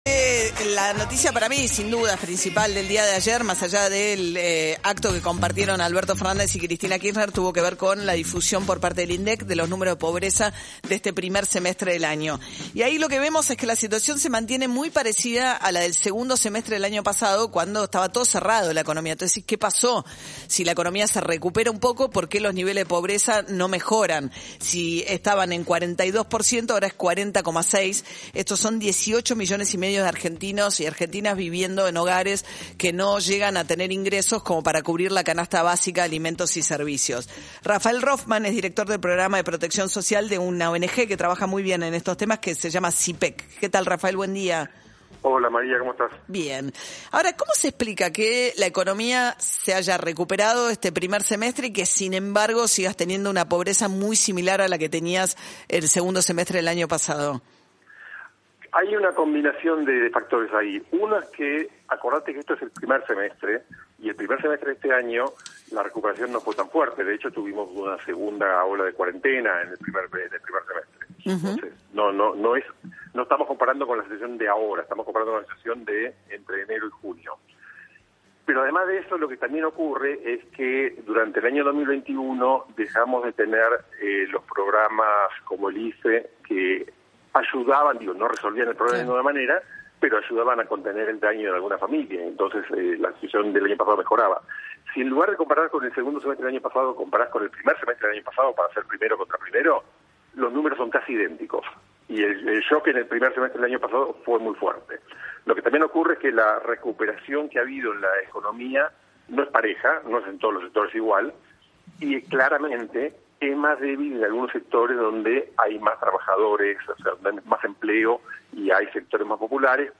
en el programa “De acá en más” de radio Urbana Play.